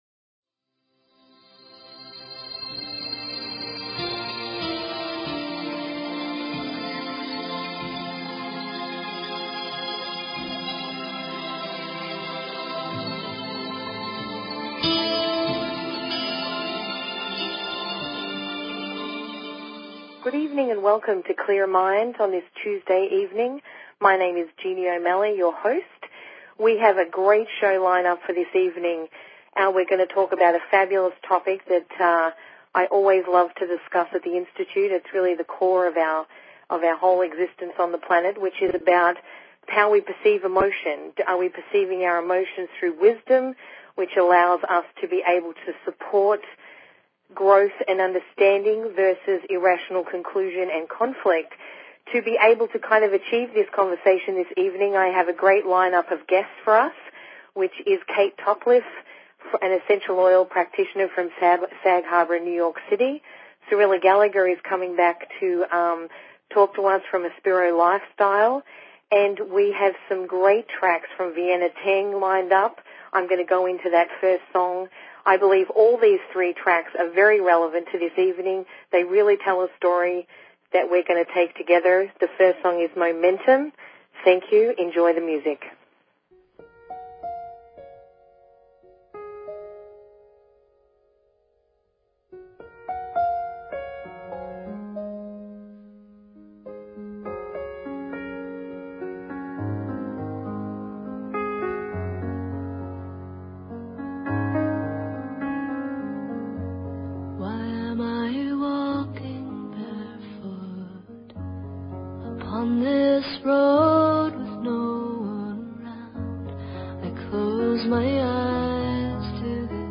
Talk Show Episode
Tonight's show will be one hour long and will include music, conversation and guest appearances.